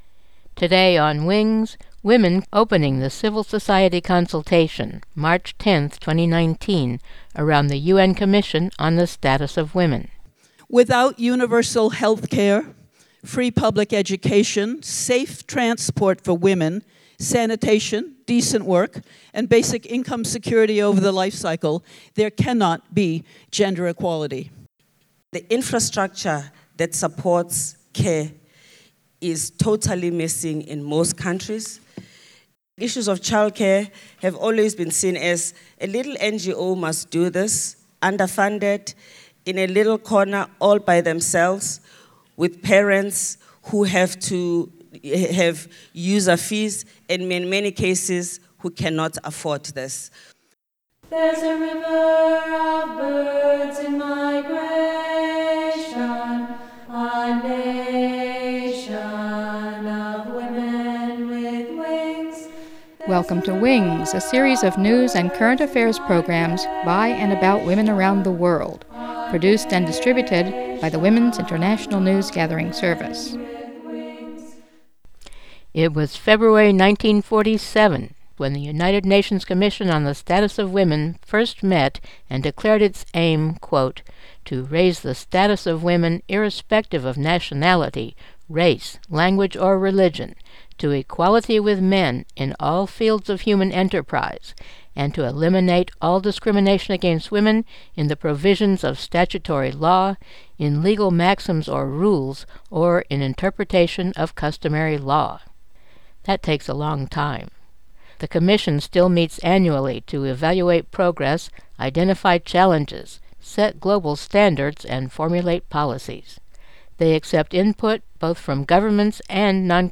featured speakers include Phumzile Mlambo-Ngcuka, head of UN Women